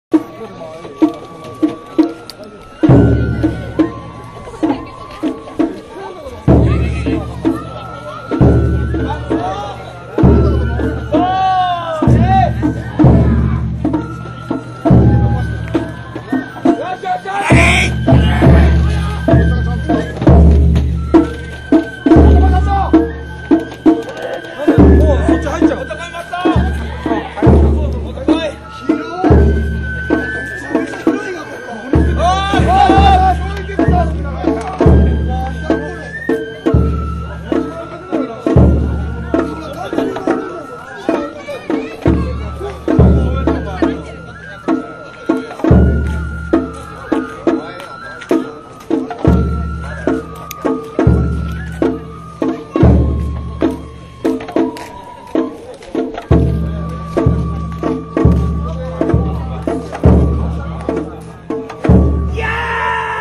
尾張の山車囃子～横須賀
社切りを遅くした曲。道行きの曲として演奏される横須賀の代表的な囃子。